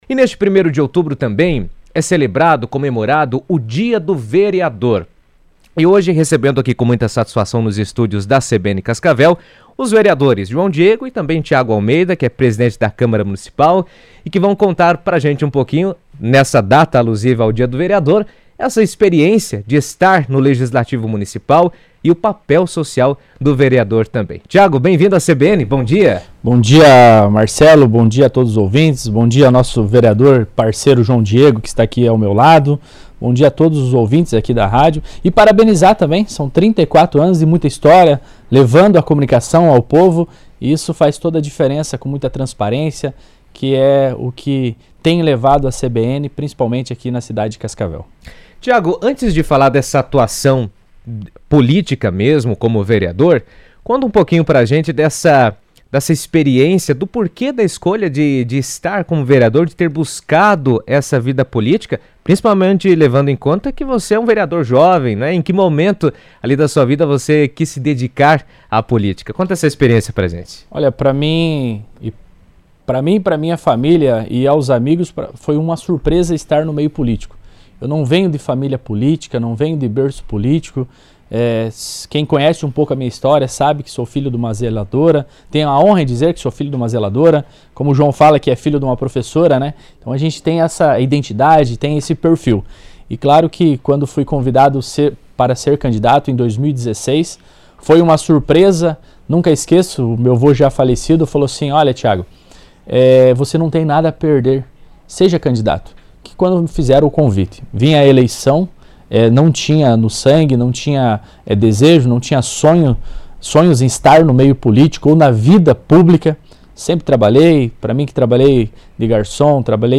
O Dia do Vereador, celebrado nesta data, ressalta a importância do trabalho dos legisladores municipais na formulação de políticas e na representação da população. Na CBN, os vereadores Tiago Almeida, presidente da Câmara, e João Diego falaram sobre o papel da categoria e os desafios da atuação legislativa.